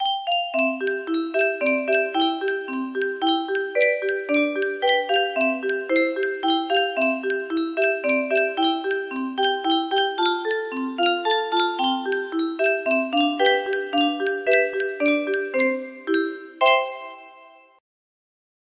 Pirouette cacahuète music box melody